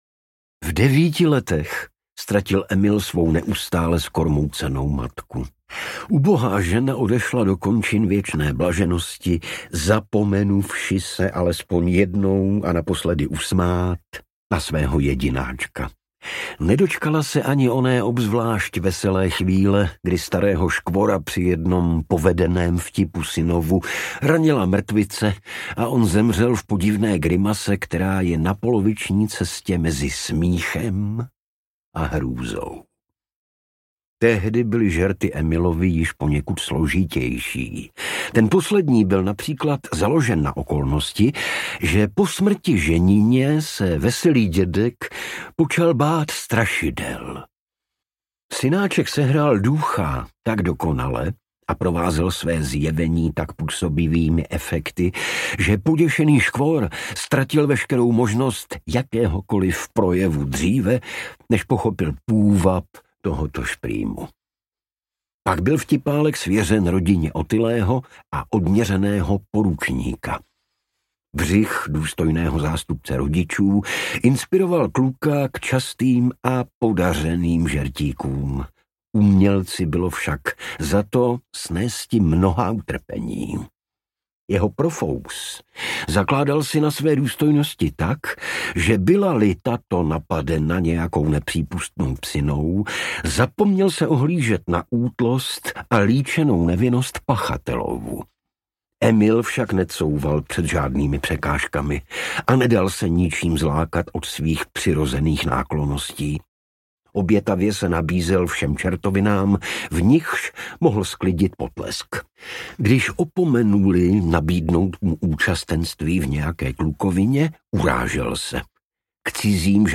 Muž sedmi sester audiokniha
Ukázka z knihy